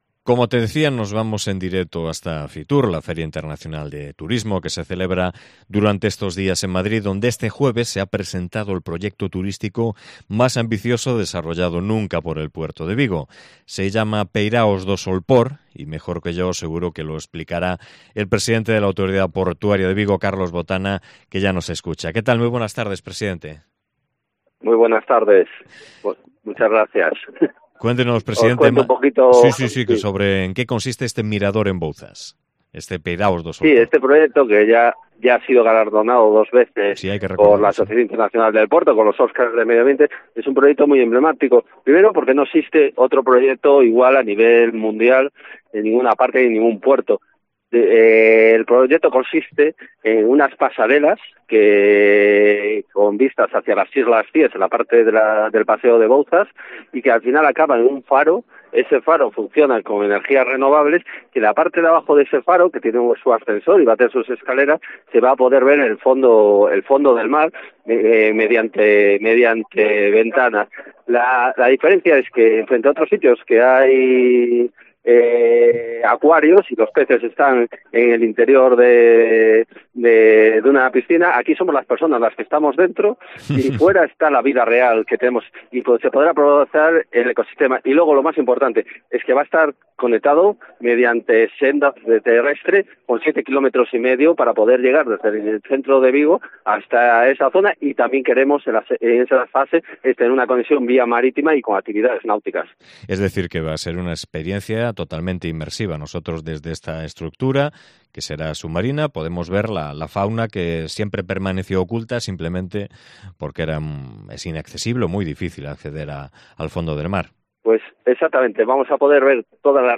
Entrevista con Carlos Botana, presidente de la Autoridad Portuaria de Vigo